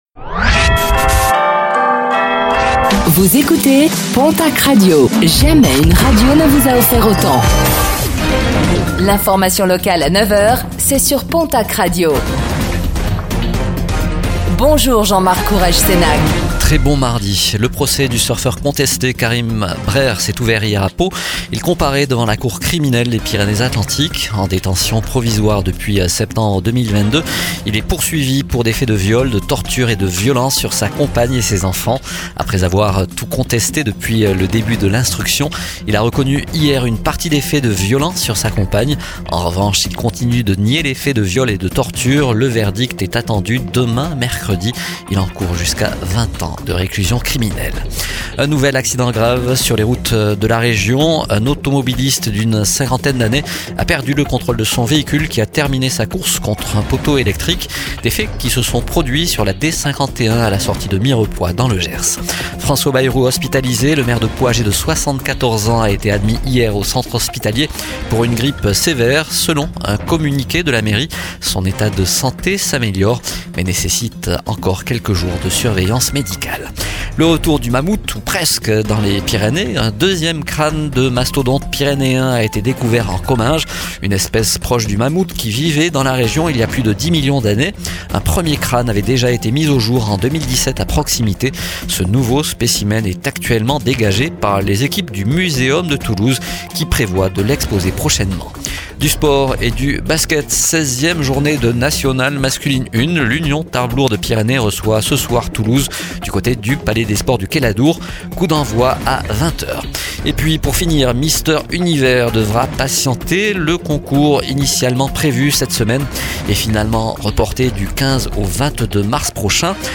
Réécoutez le flash d'information locale de ce mardi 16 décembre 2025